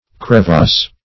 Crevasse \Cre`vasse"\ (kr?`v?s"), n. [F. See Crevice.]